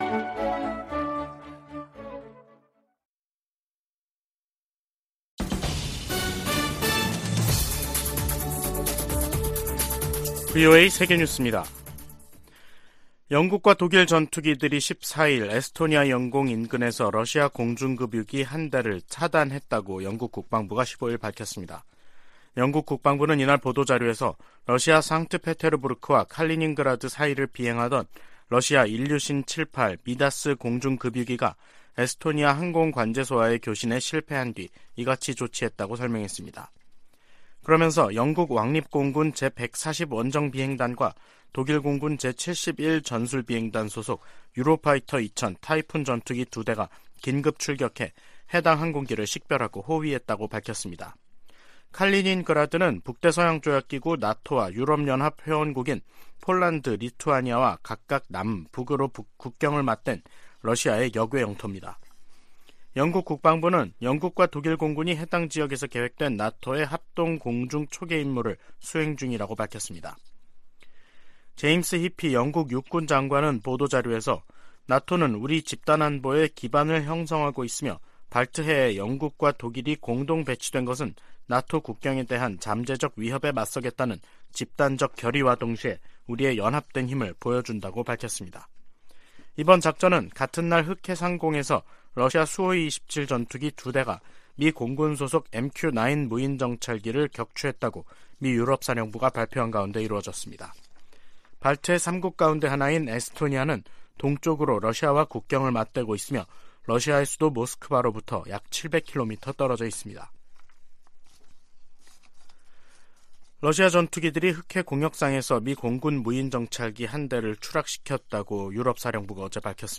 VOA 한국어 간판 뉴스 프로그램 '뉴스 투데이', 2023년 3월 15일 3부 방송입니다. 북한은 14일 황해남도 장연에서 지대지 탄도미사일 2발 사격 훈련을 실시했다고 다음날 관영매체를 통해 발표했습니다. 북한의 최근 미사일 도발이 미한 연합훈련을 방해할 의도라면 성공하지 못할 것이라고 미 백악관이 지적했습니다. 일본 방문을 앞둔 윤석열 한국 대통령은 일본 언론과의 인터뷰에서 북한 핵 위협에 맞서 미한일 협력의 중요성을 강조했습니다.